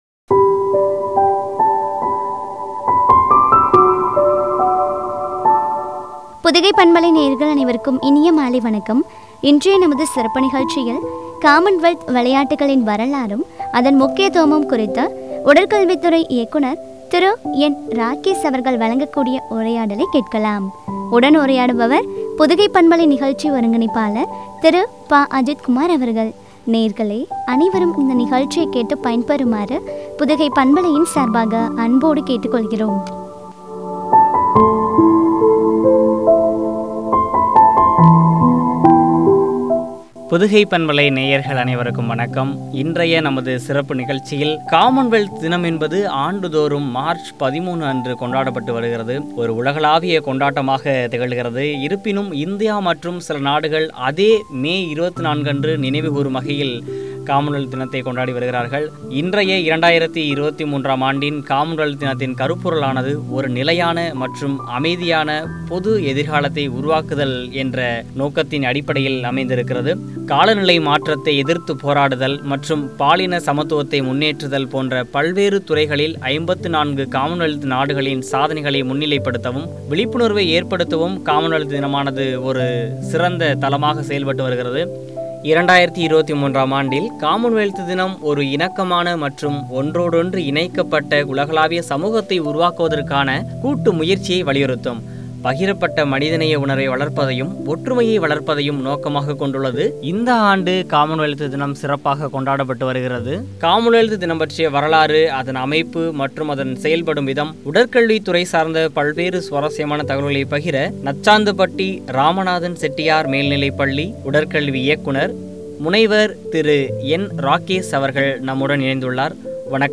காமன்வெல்த் விளையாட்டுகள் – வரலாறும், முக்கியத்துவமும் பற்றிய உரையாடல்.